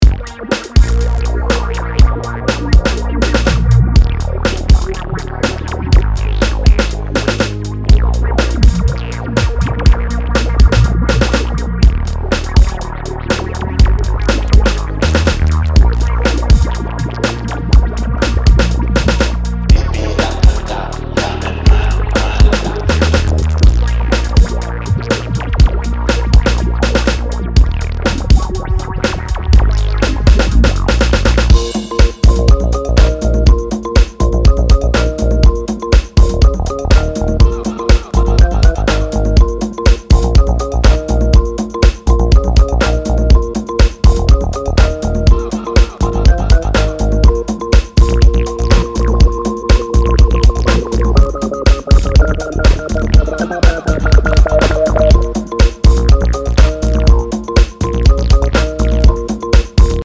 ハウス、ファンク、エレクトロといった多様なジャンルを行き来する、コミカルかつ躍動感溢れる内容に仕上がっています！